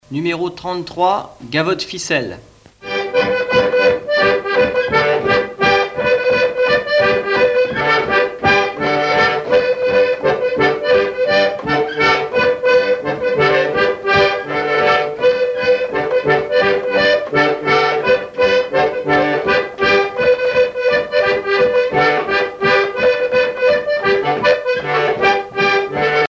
danse : gavotte bretonne
Pièce musicale éditée